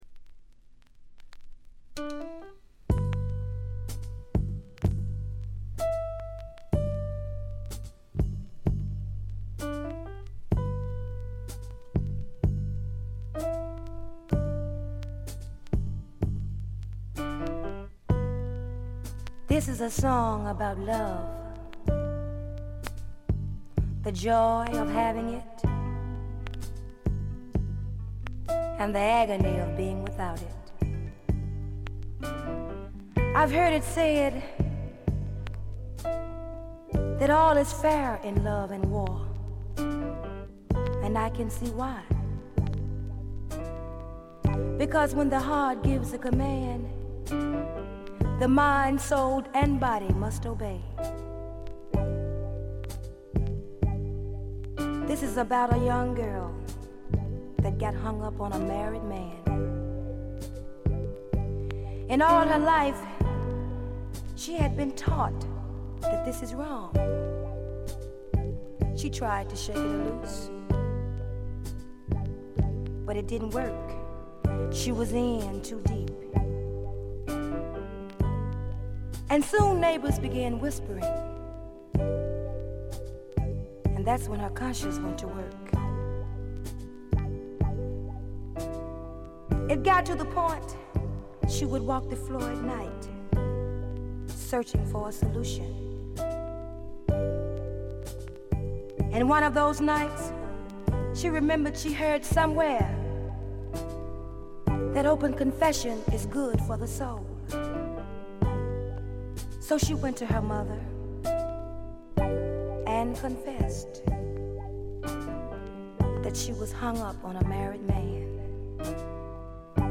栄光の60年代スタックス・ソウルの正統なる継承者とも言えるディープなヴォーカルと適度なメロウさがたまらないです。
試聴曲は現品からの取り込み音源です。